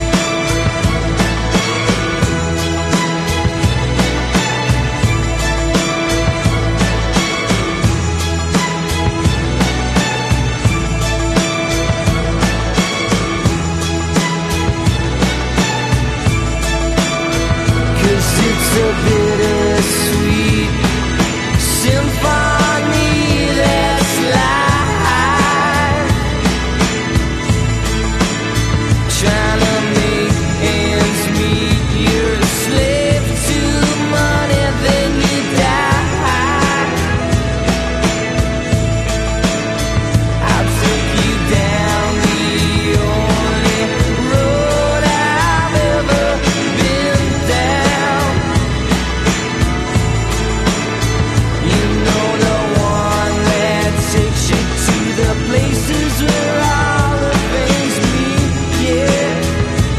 Air Canada Ifly 737 Max Sound Effects Free Download